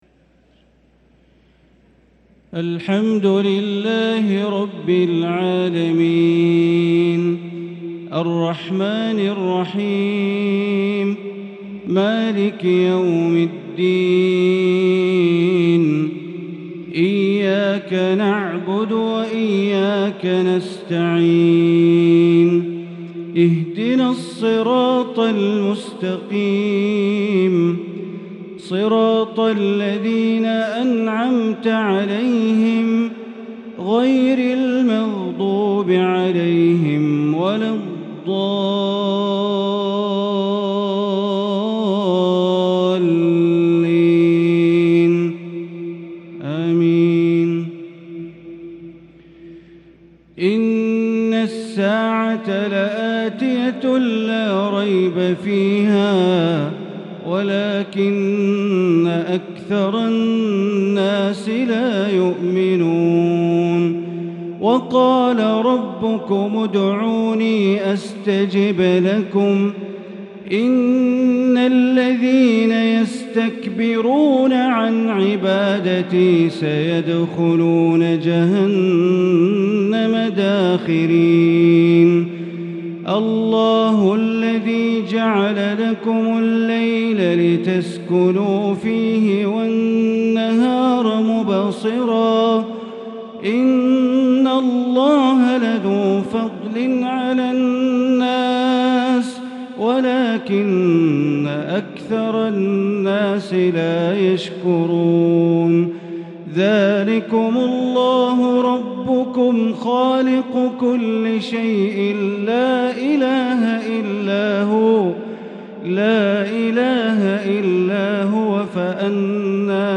عشاء الاثنين 3 رمضان 1443هـ من سورة غافر | Isha prayer from surat Ghafir 4-4-2022 > 1443 🕋 > الفروض - تلاوات الحرمين